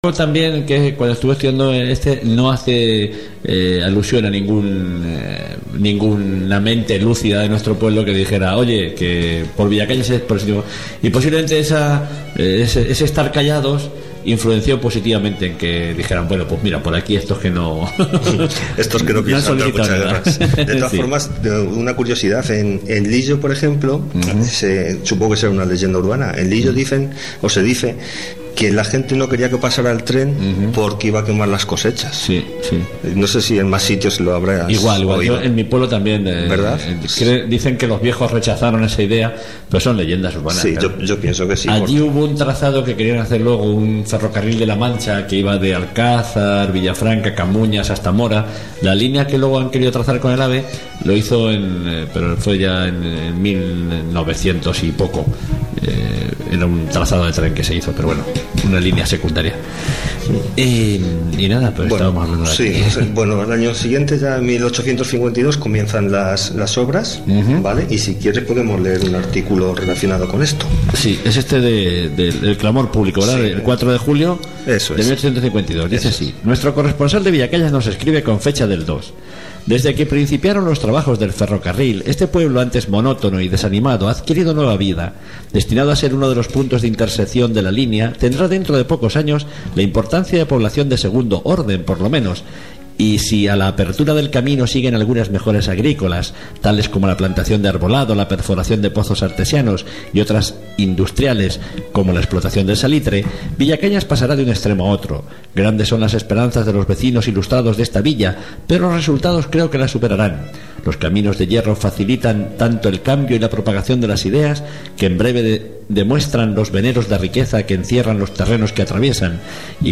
SI QUIERES OIR LA SECCION LOCAL DEL PROGRAMA DE RADIO VILLACAÑAS "LA BIBLIOTECA EN EL AIRE" QUE SE EMITIO EL DIA 14 DE FEBRERO DE 2.013 EN EL QUE HABLAMOS DE LA LLEGADA DEL TREN A VILLACAÑAS DESCARGA LOS SIGUIENTES ARCHIVOS: